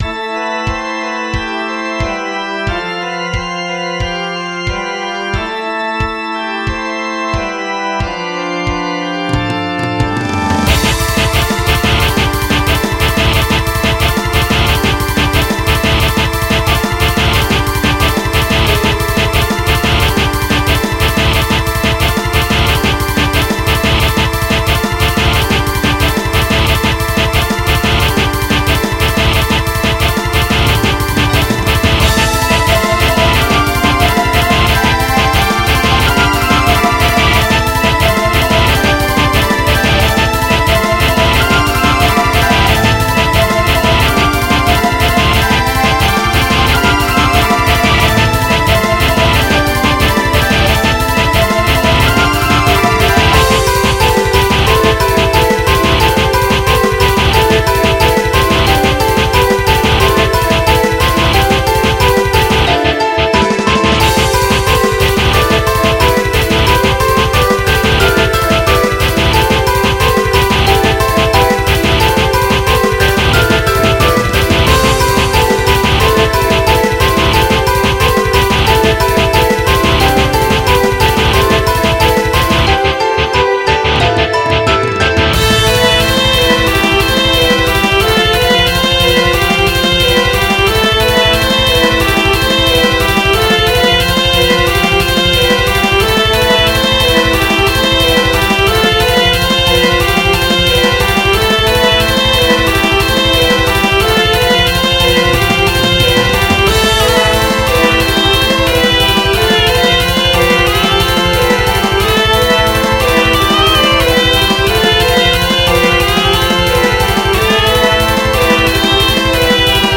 MIDI 49.49 KB MP3